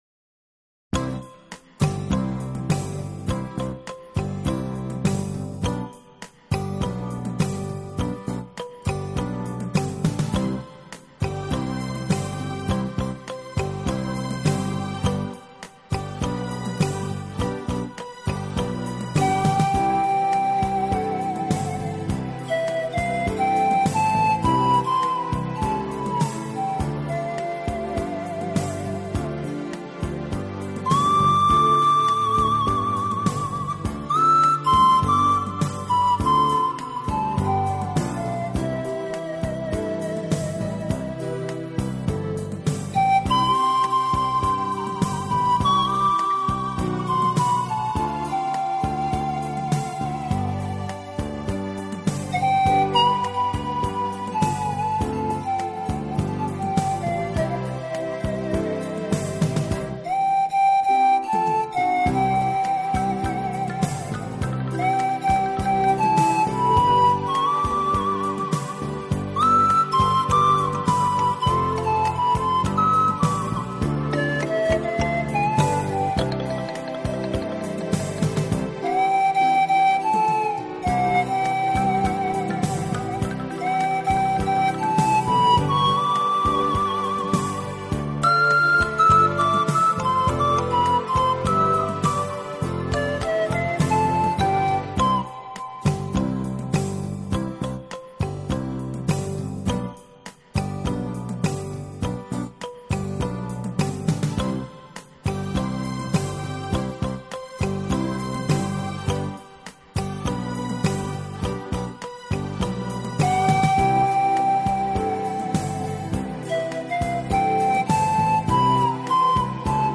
排箫
排笛的声音略带瘖哑，有着一种苍凉的独特风味，十分迷人，因此特别